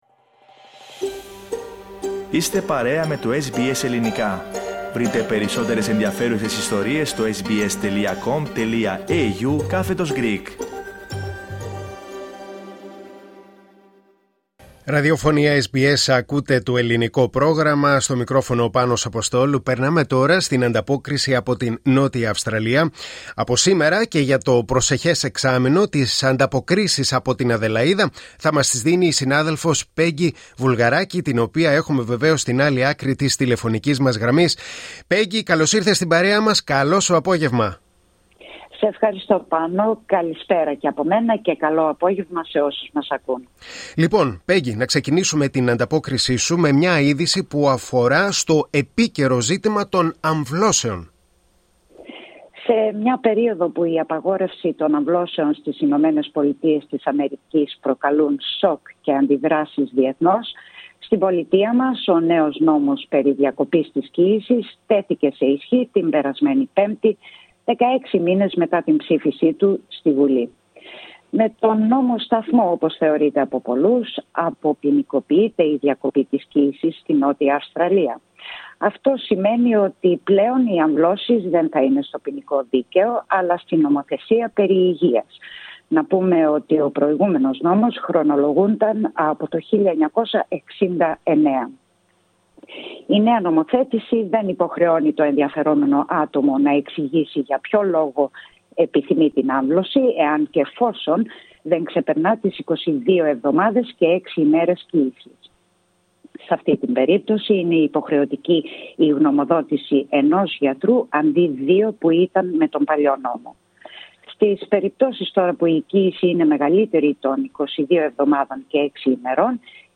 H εβδομαδιαία ανταπόκριση από Αδελαΐδα της Νότιας Αυστραλίας.